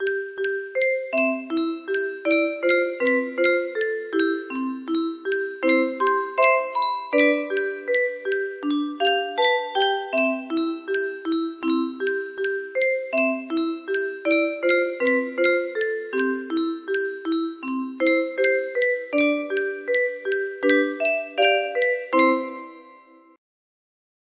How Great Thou Art - Traditional music box melody